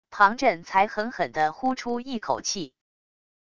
庞震才狠狠地呼出一口气wav音频生成系统WAV Audio Player